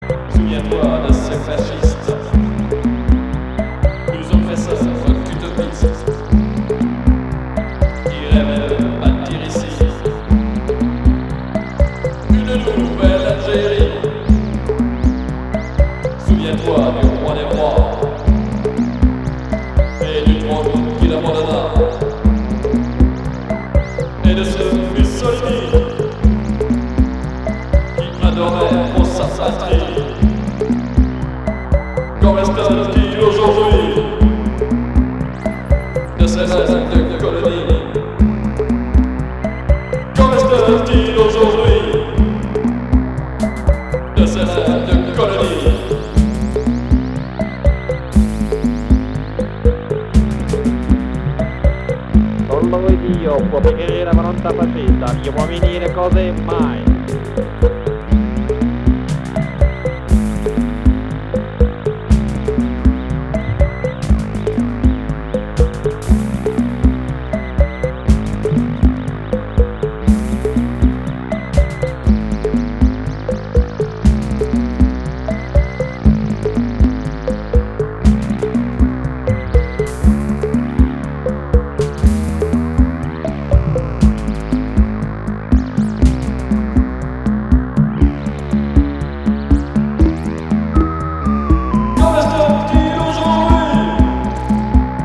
you can hear the use of synths, guitars, and drum machines.
Romantic + Cold :wink: